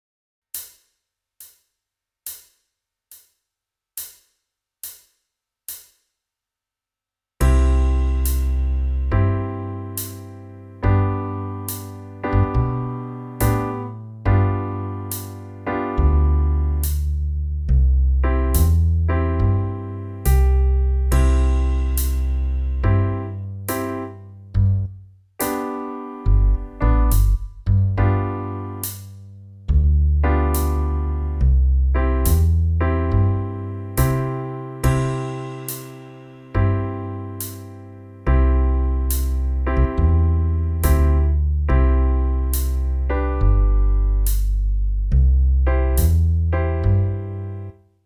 Soundbeispiel – Melodie & Band sowie Band alleine: